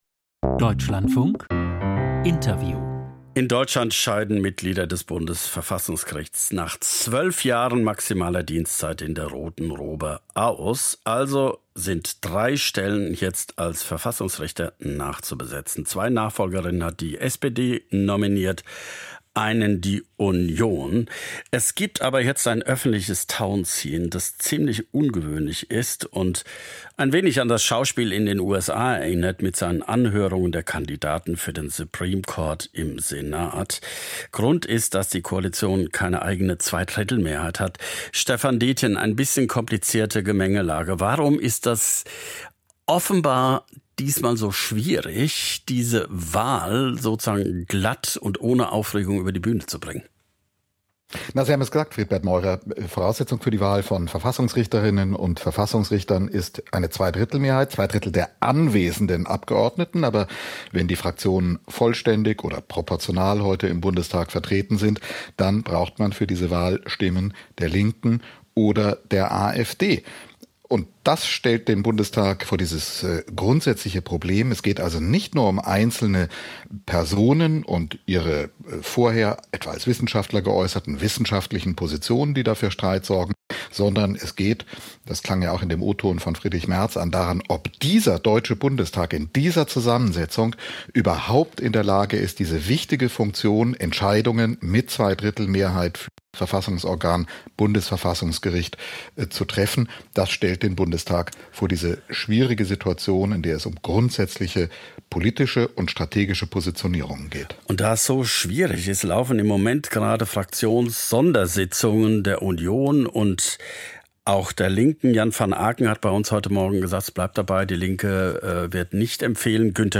Verfassungsrichterwahl - Interview mit Britta Haßelmann, B90/Grüne, Fraktionsvorsitzende